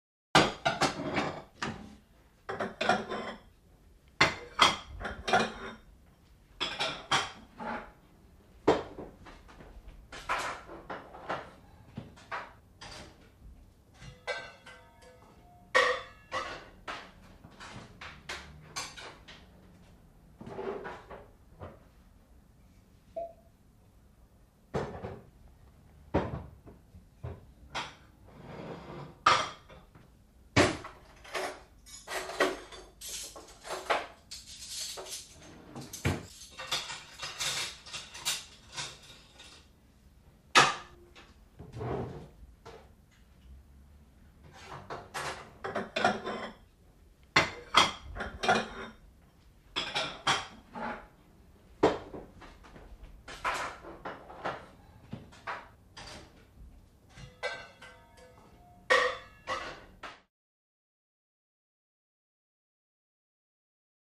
Kitchen - Dish & Silverware Movement